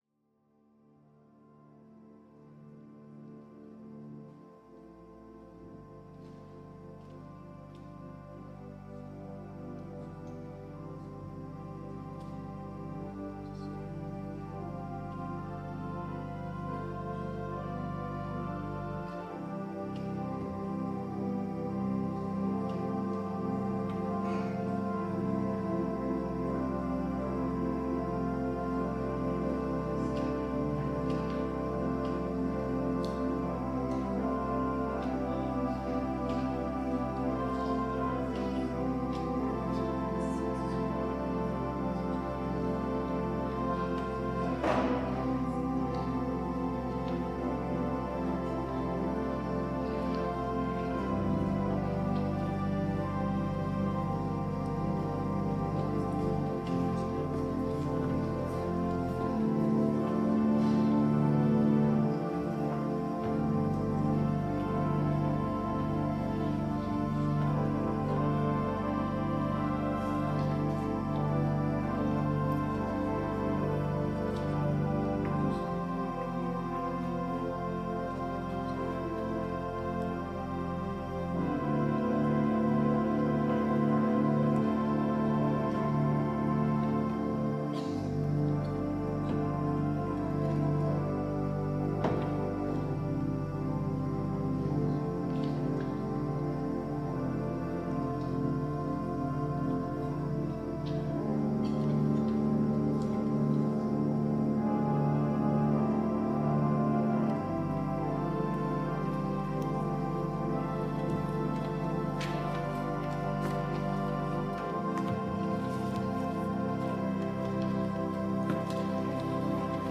WORSHIP - 4:00 p.m. Last Sunday after Pentecost